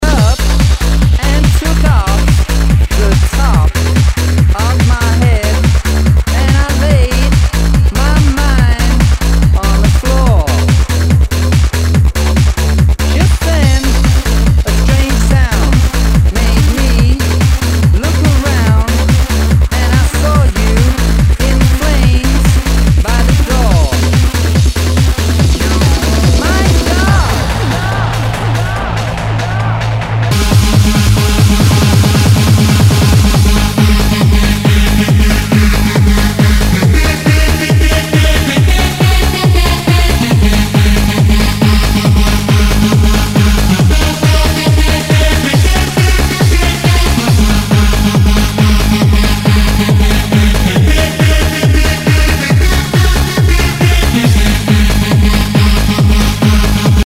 HOUSE/TECHNO/ELECTRO
ナイス！ハード・ハウス / トランス！